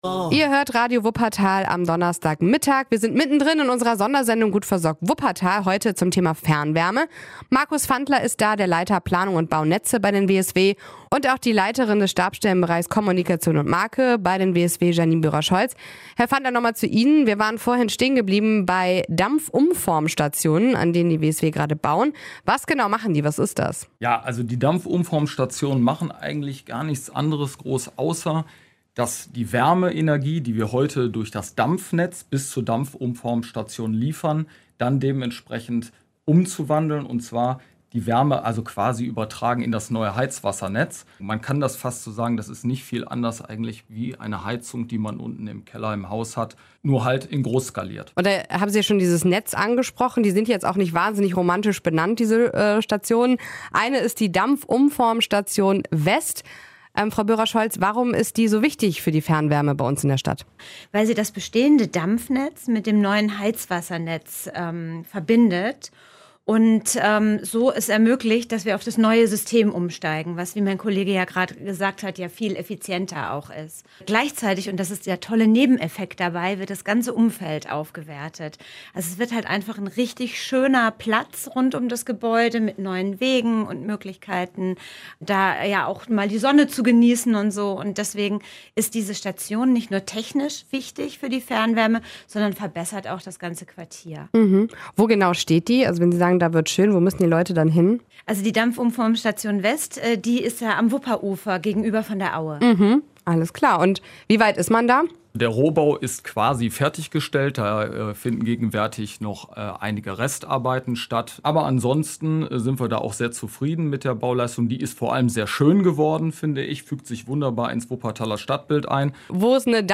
Das ist Thema in der Sondersendung WSW Gut versorgt in Wuppertal.